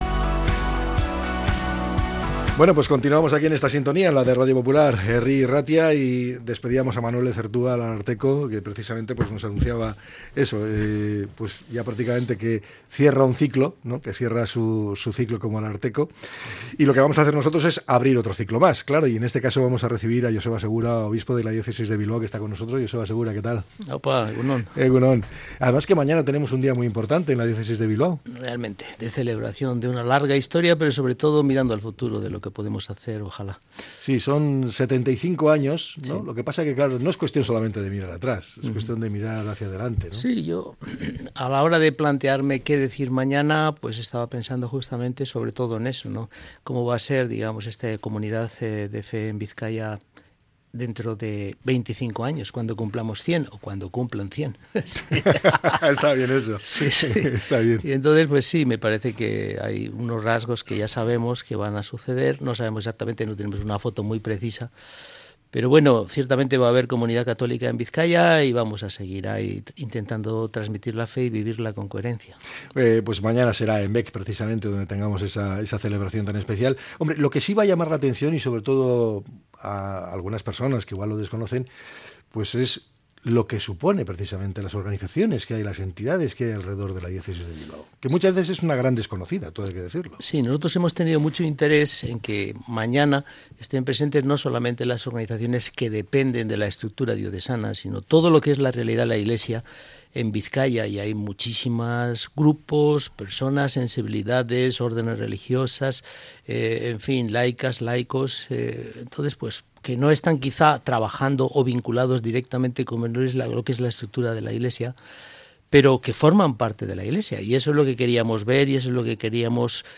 El obispo, sobre Gaza A finales de la semana pasada, el obispo de Bilbao, concedió una entrevista a Radio Popular.